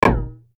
Golf_Hit_Barrier_1.ogg